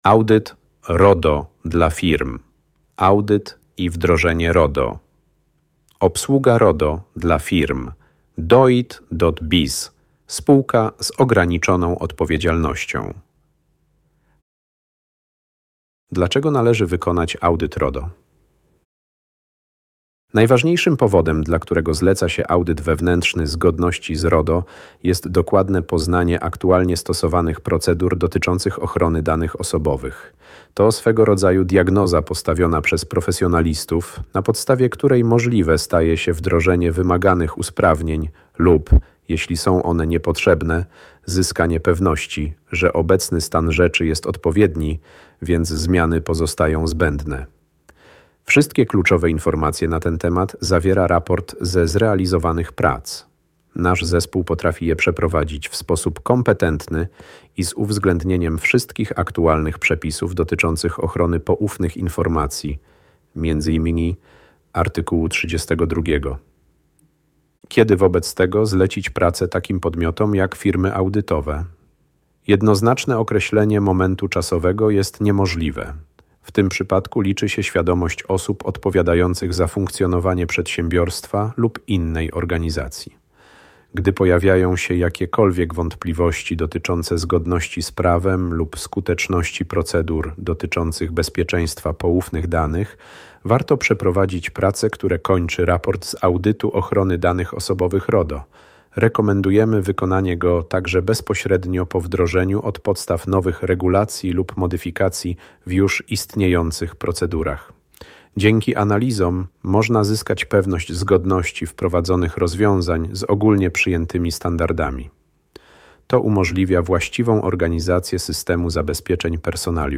Dowiedz się w kilka minut, na czym naprawdę polega audyt RODO i jak może on pomóc Twojej firmie uniknąć kosztownych błędów w ochronie danych osobowych. W tym krótkim podcaście nasz ekspert wyjaśnia: 1. czym różni się audyt RODO od wdrożenia, 2. jakie błędy popełniają najczęściej firmy, 3. jak wygląda raport z audytu i co zawiera.